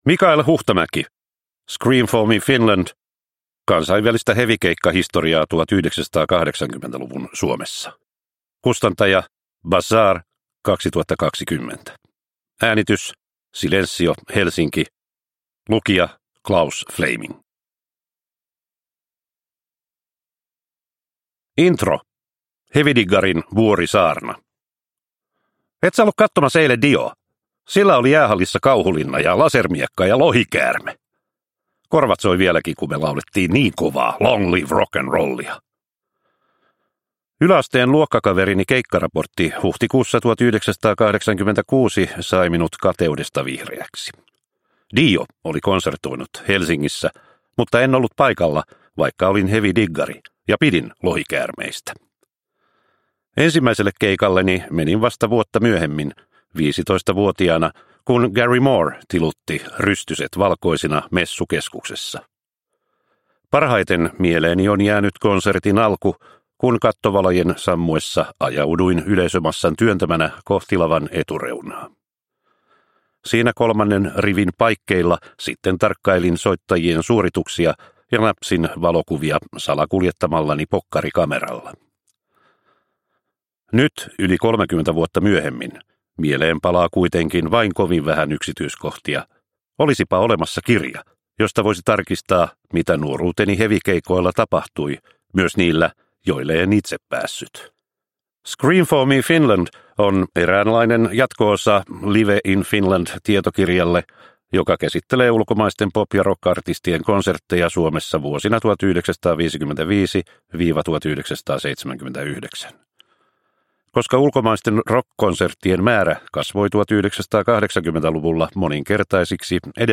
Scream for me Finland! – Ljudbok – Laddas ner